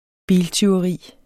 Udtale [ ˈbiːl- ] Betydninger tyveri af en bil